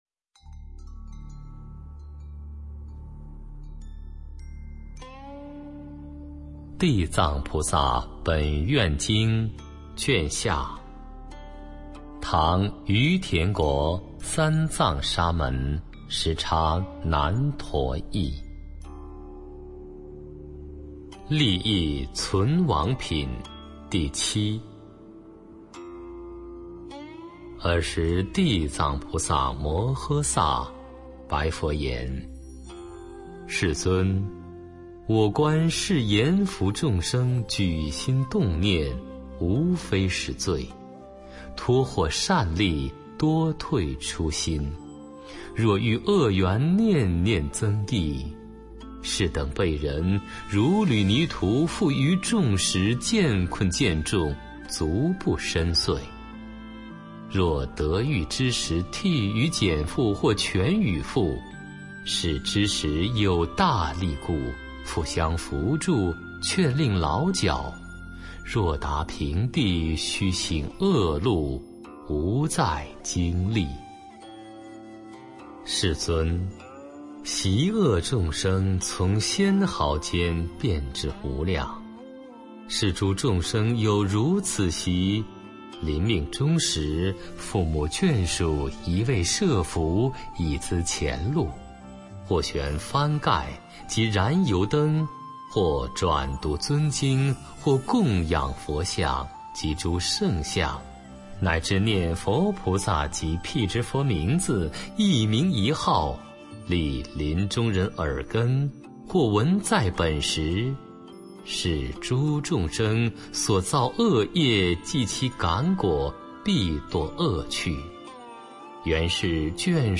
本地音频 地藏经 读诵 下卷 学习建议： 适合与上卷一起收藏，组成完整的地藏经本地听诵资源。